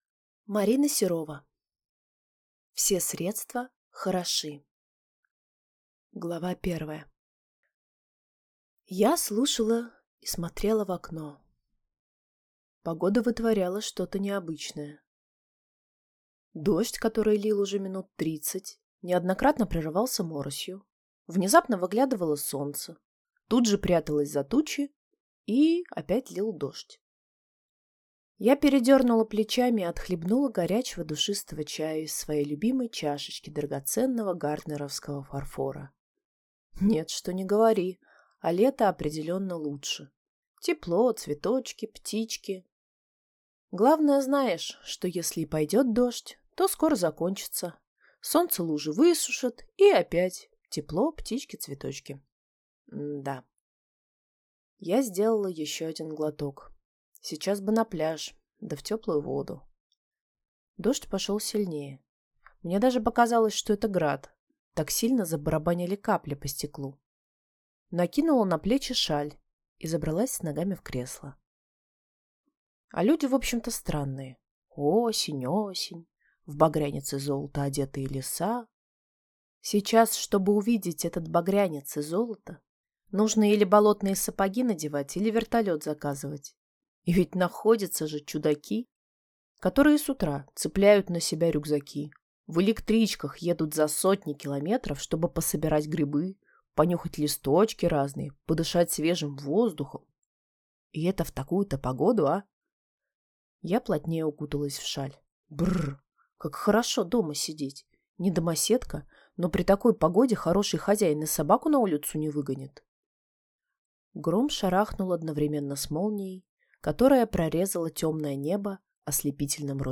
Aудиокнига Все средства хороши!